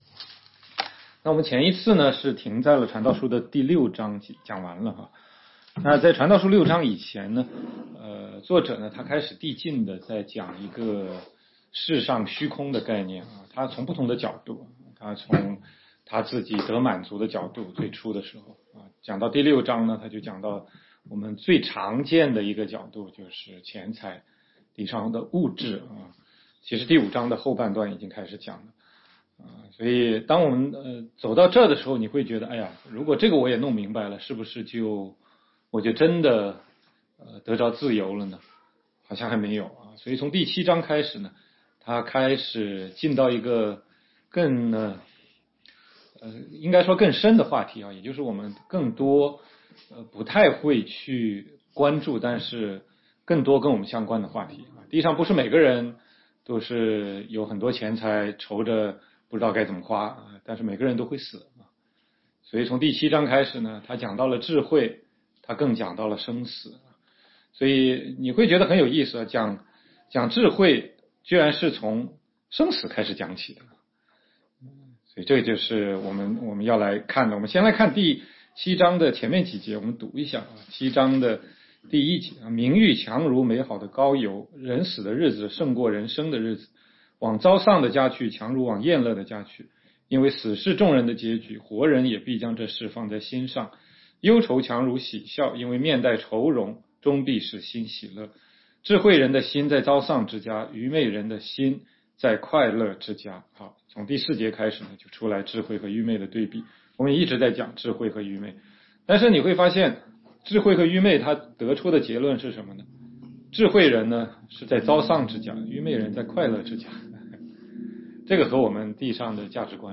16街讲道录音 - 从传道书看人生的意义：从虚空到记念造你的主（8）